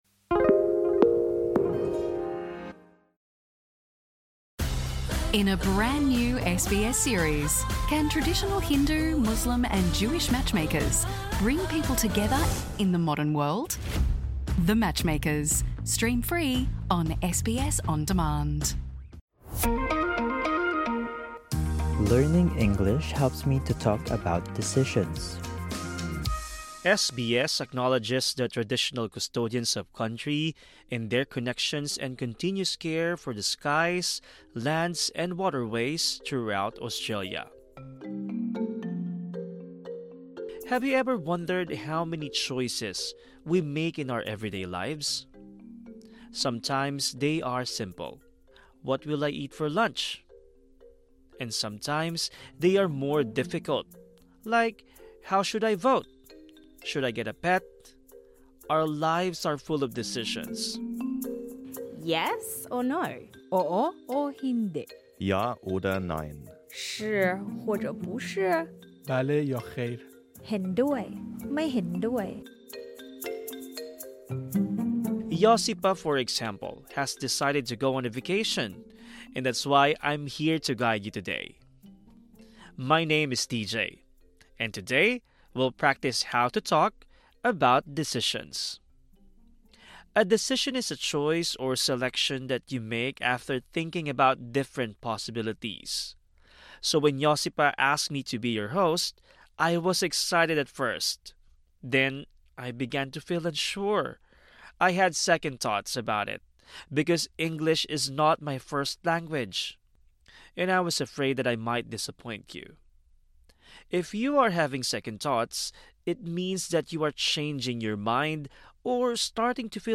Learn how to talk about easy and difficult decisions. Plus, hear migrants discuss their decisions to move to Australia.